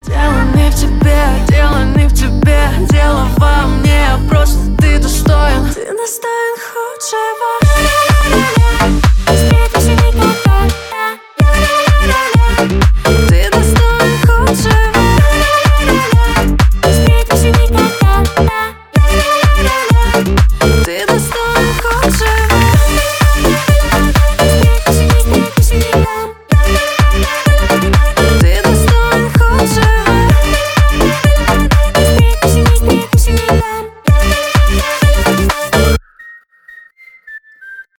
танцевальные
поп